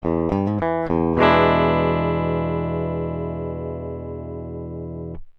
생톤의 소리입니다.
깁슨레스폴   펜더·스트라토캐스터